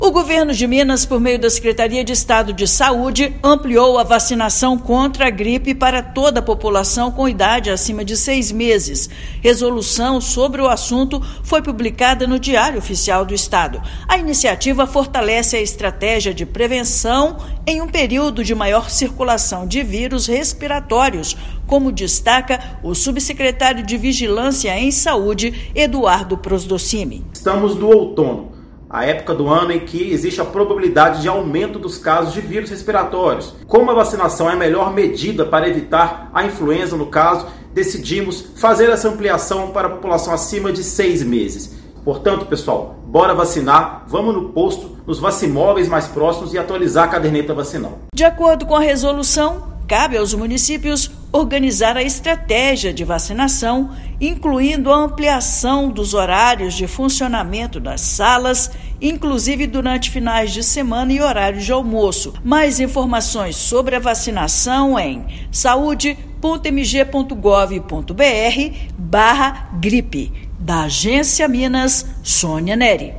[RÁDIO] Governo de Minas amplia vacinação contra a influenza para toda a população acima de 6 meses
Medida visa prevenir aumento de casos de doenças respiratórias durante o outono; Secretaria de Saúde reforça a importância da atualização do cartão de vacina. Ouça matéria de rádio.